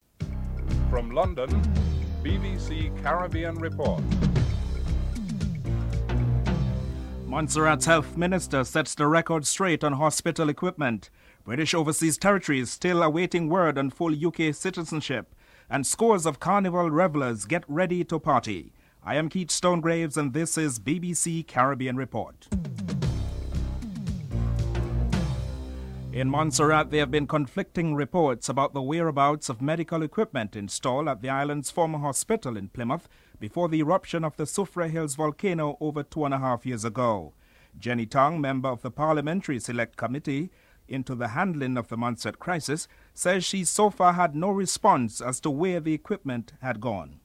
Health Minister Adelaine Truitt sets the record straight about the equipment (00:26-03:30)